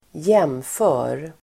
Uttal: [²j'em:fö:r]